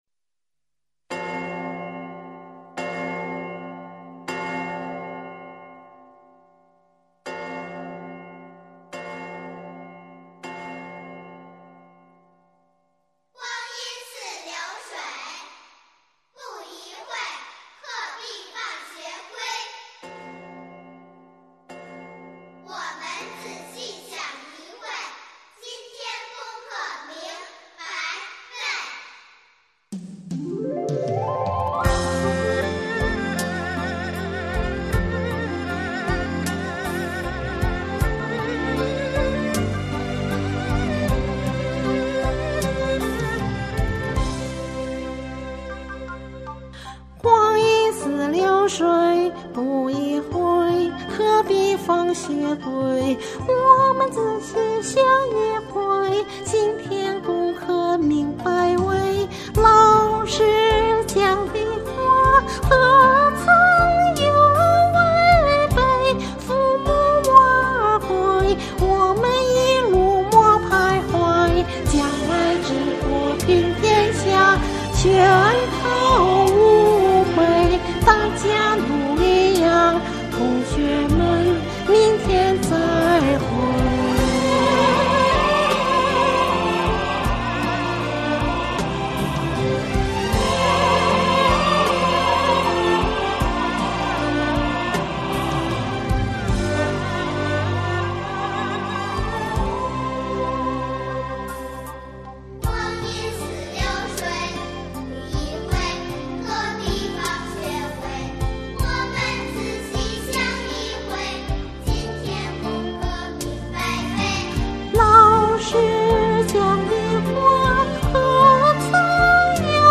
校园夕歌》 很老的学堂乐歌
歌的曲调采自当时全国流传的民间乐曲《老六板》，旋律深沉而宽广，一字一板，浑厚自然。
伴奏里的朗诵和伴唱是该伴奏自身带的。